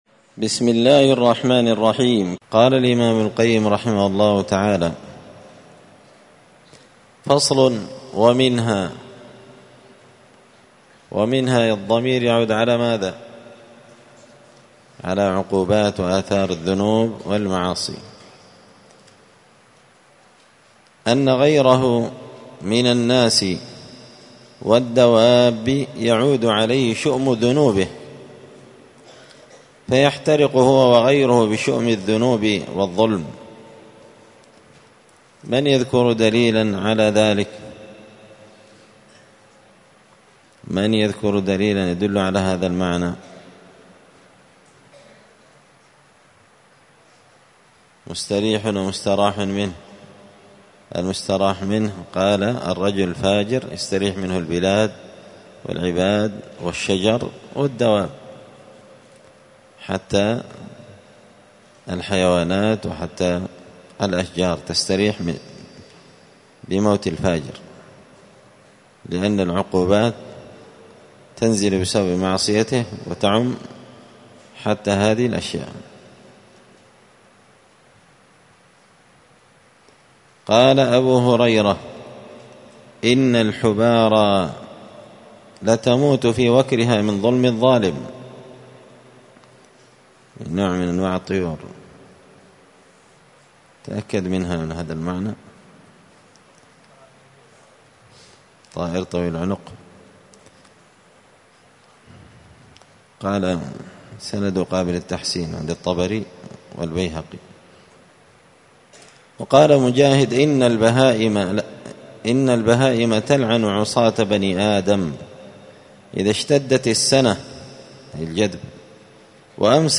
الدروس الأسبوعية